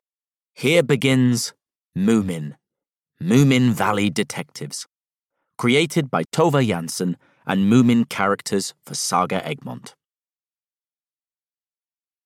Moominvalley Detectives (EN) audiokniha
Ukázka z knihy